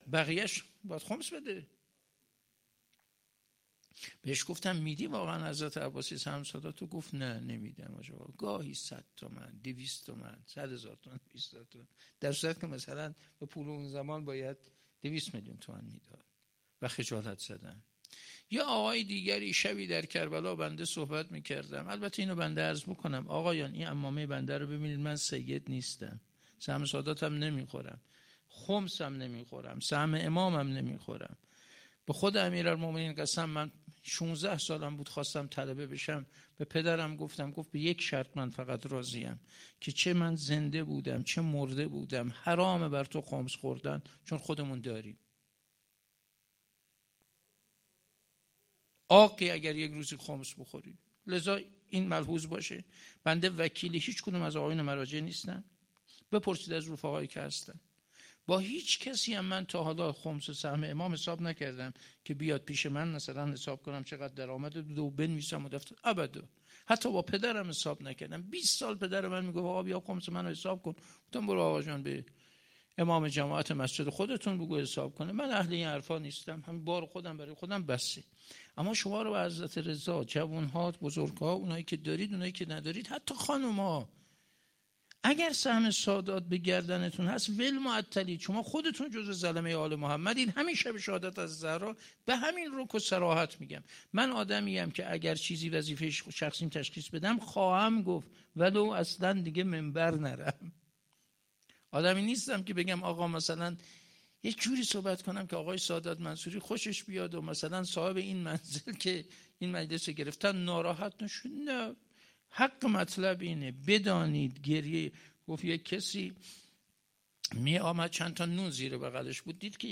29 دی 97 - سالن همایش های آنلاین - اگر سهم سادات به گردن شما است
فاطمیه 97